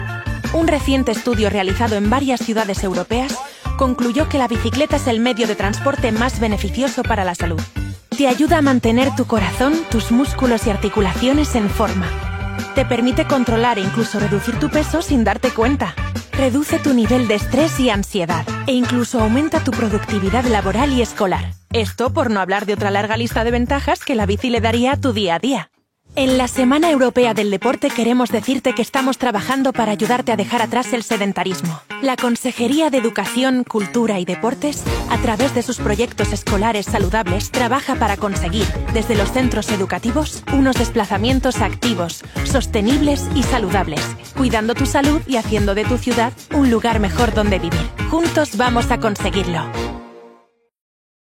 Voix off
Spot (espagnol)
6 - 30 ans - Mezzo-soprano